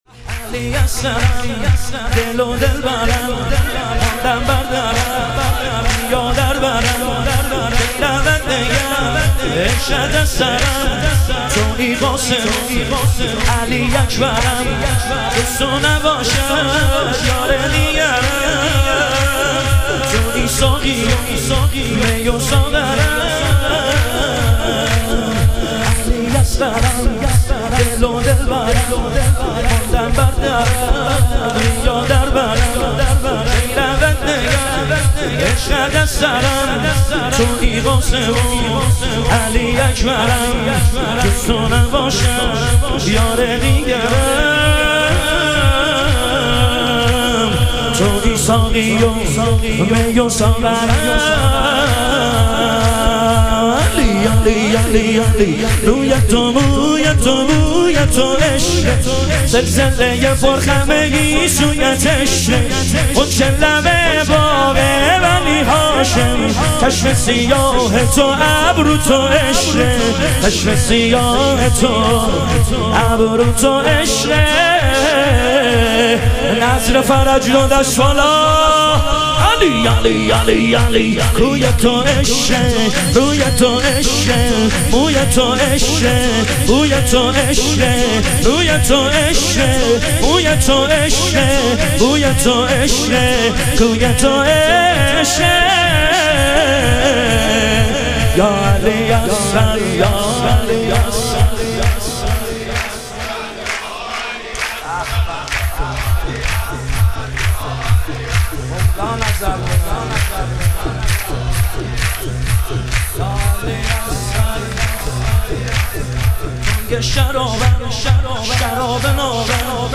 ظهور وجود مقدس امام جواد و حضرت علی اصغر علیهم السلام - تک